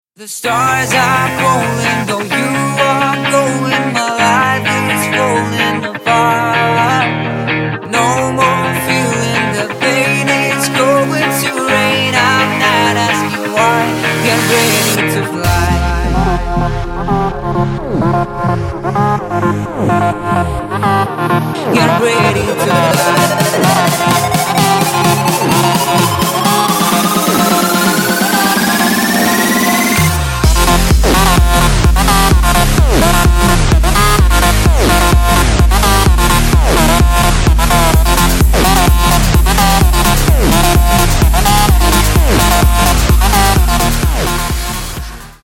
• Качество: 160, Stereo
dance